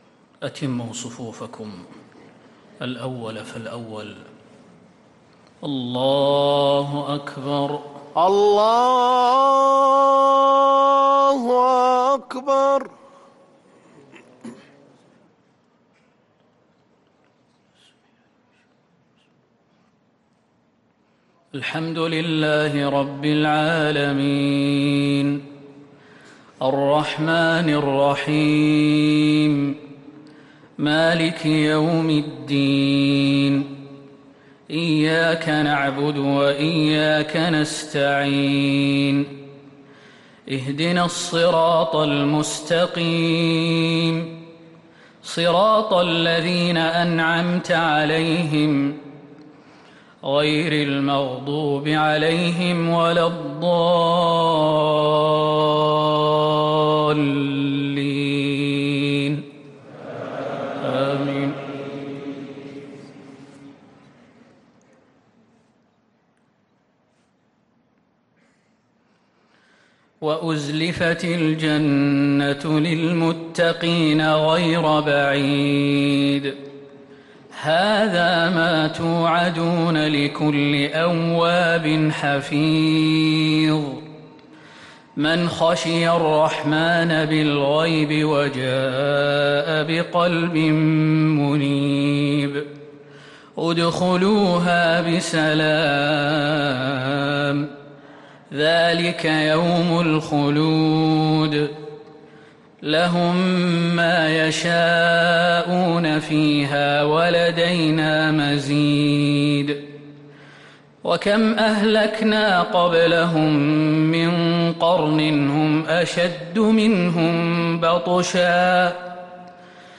عشاء الخميس 5 صفر 1444هـ من سورتي ق و الذاريات | Isha prayer from Sarah Qaf and Adh-dhariyat 1-9-2022 > 1444 🕌 > الفروض - تلاوات الحرمين